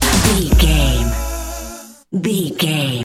Ionian/Major
D
synthesiser
drum machine
electric guitar
drums
strings
90s